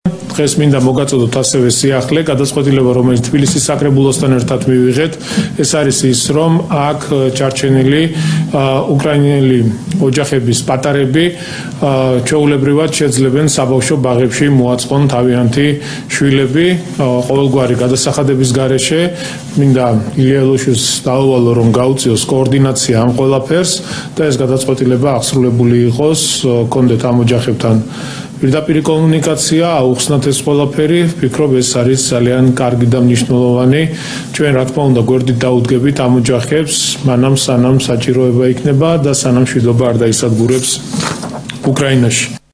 კახა კალაძემ თბილისის მთავრობის სხდომაზე თქვა, რომ მათ რაიმე გადასახადის გადახდა არ მოუწევთ.
კახა კალაძის ხმა